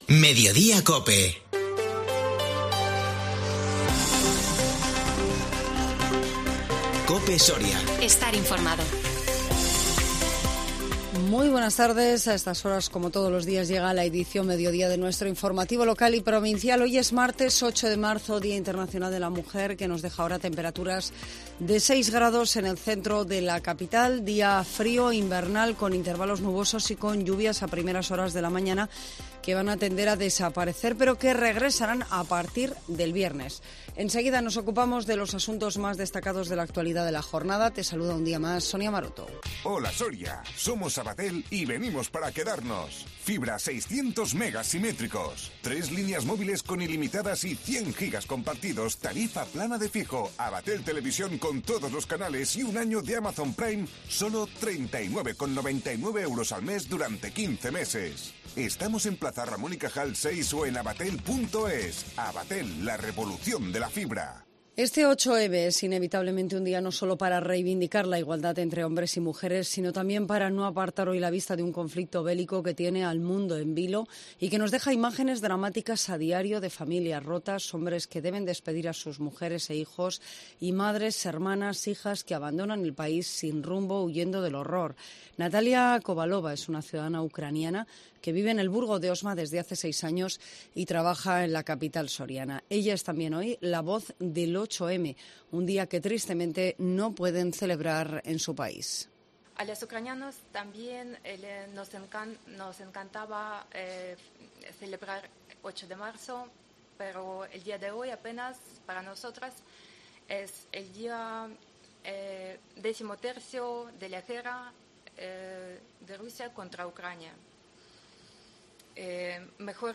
INFORMATIVO MEDIODÍA COPE SORIA 8 MARZO 2022